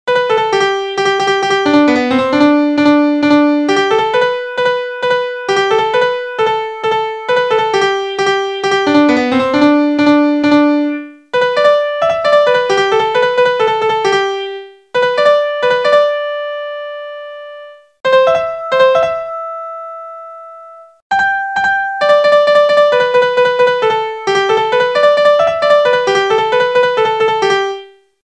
アメリカ合衆国の民謡。
日本で一番普及しているフォークダンスの一つ。